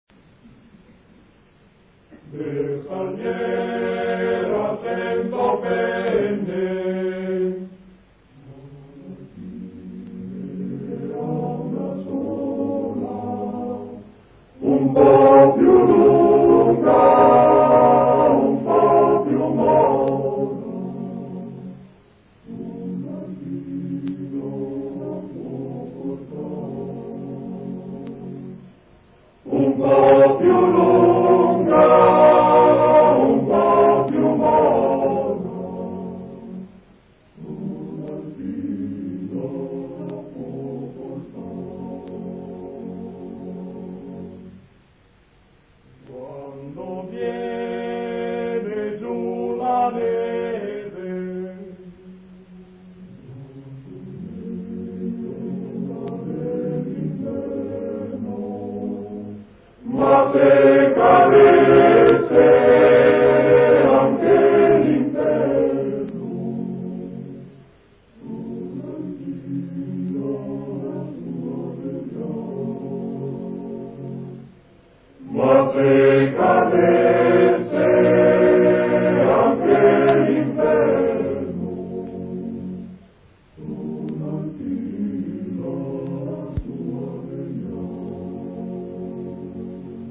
Bersaglier ha cento penne [ voci virili ] Bersaglier ha cento penne ma l’alpin ne ha una sola un po’ più lunga un po’ più mora sol l’alpin la può portar.